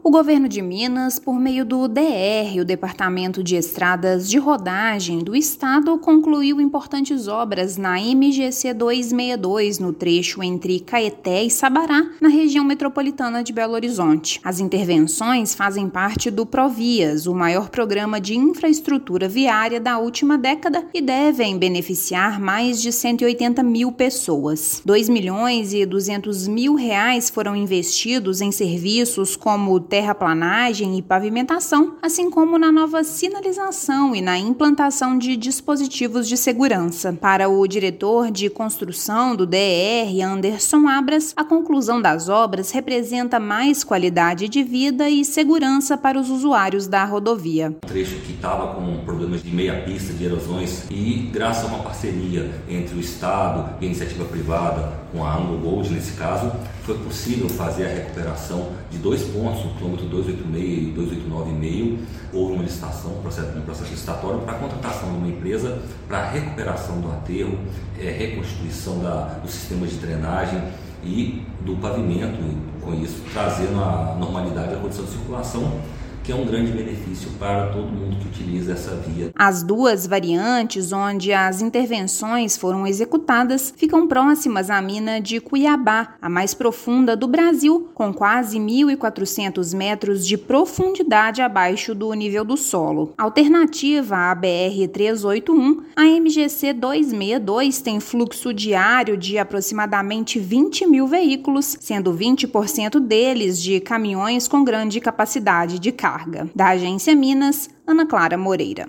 Melhorias foram executadas entre Sabará e Caeté e acabam com o transtorno de tráfego em meia pista na rodovia. Ouça matéria de rádio.